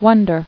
[won·der]